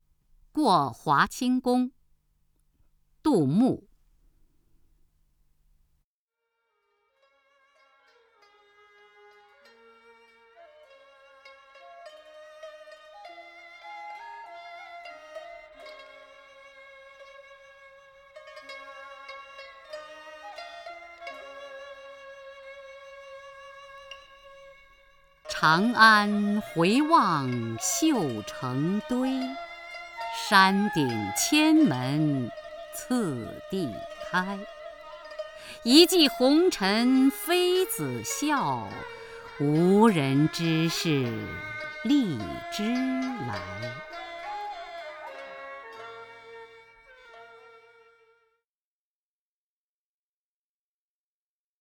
雅坤朗诵：《过华清宫绝句·其一》(（唐）杜牧) （唐）杜牧 名家朗诵欣赏雅坤 语文PLUS